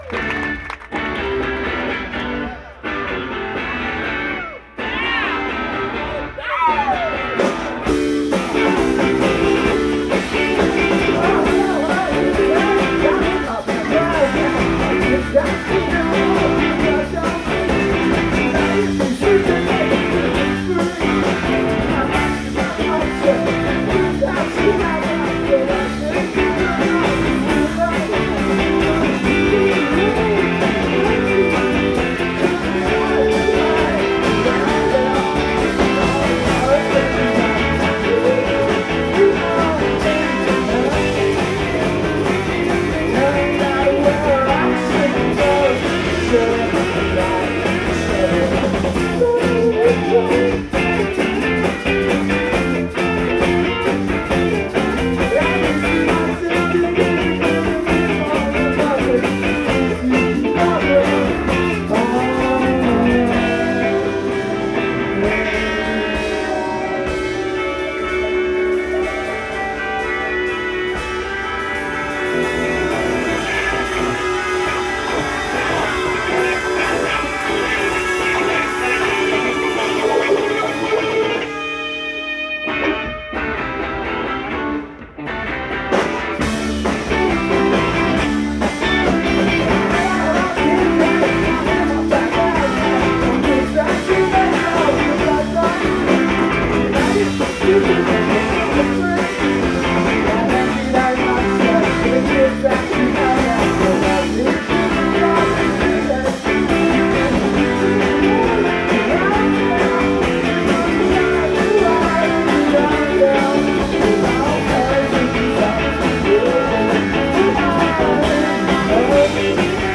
Live at All Tomorrow’s Parties NYC 2008
in Monticello, NY